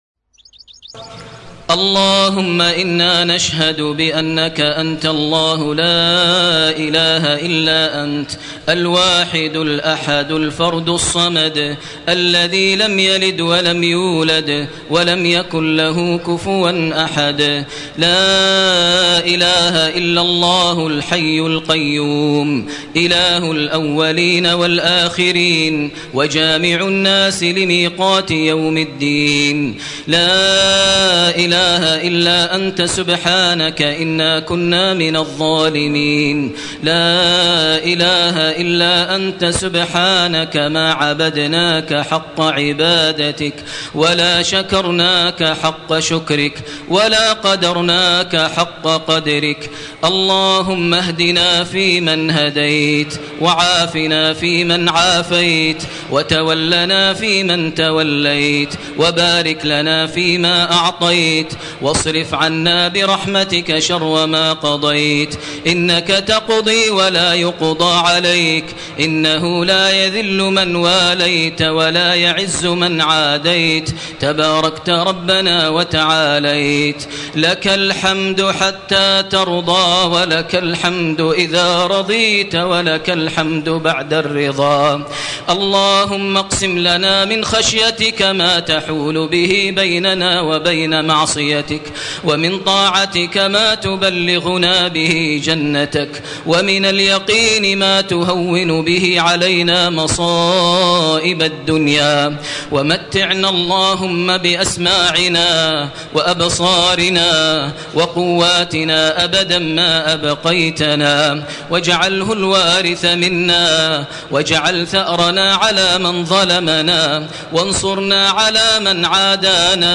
Ansarallah دعاء للشيخ ماهر المعقيلي